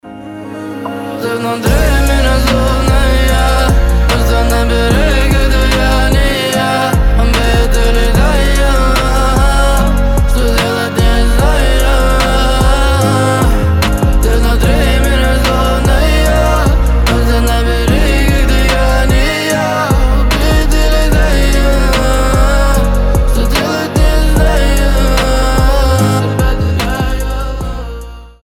мужской голос
лирика